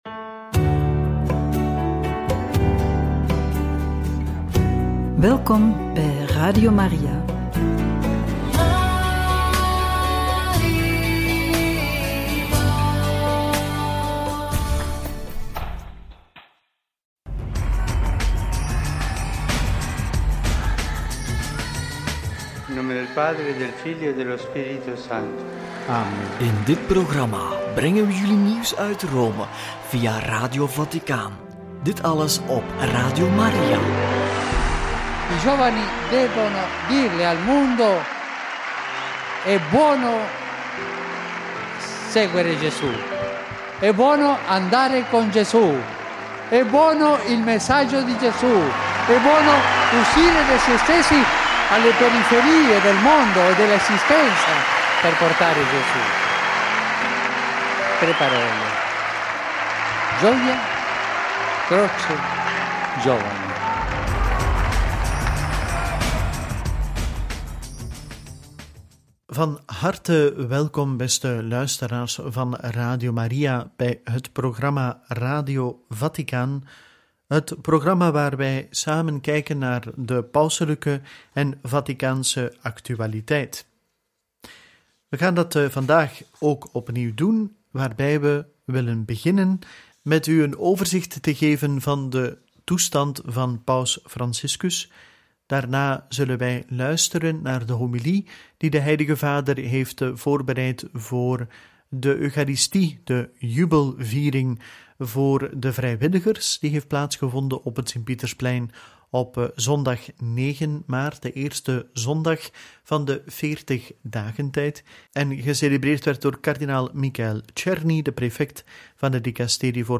Stem van de paus voor de 1e keer te horen sinds ziekenhuisopname – Homilie Jubileum voor de vrijwilligers – Angelus 9/3 – Radio Maria